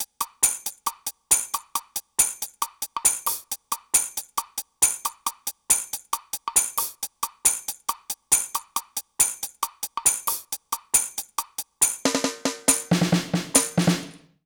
British REGGAE Loop 143BPM (NO KICK).wav